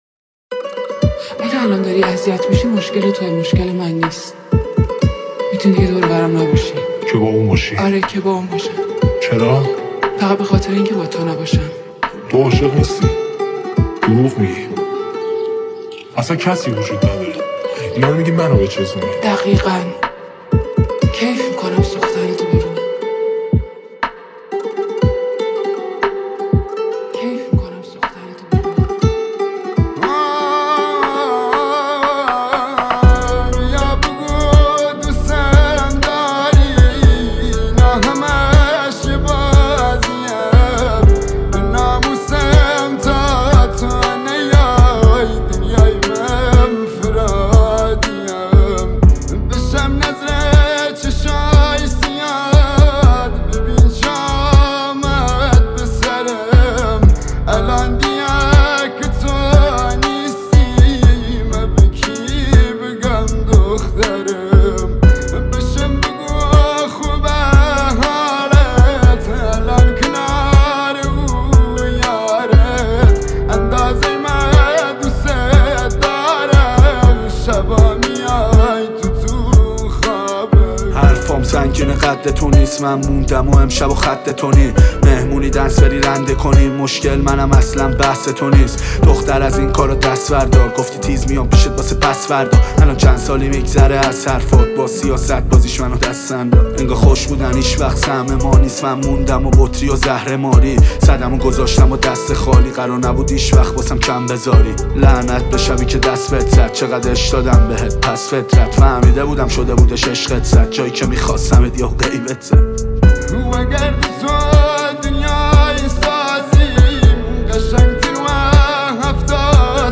ترانه بسیار سوزناک و پرطرفدار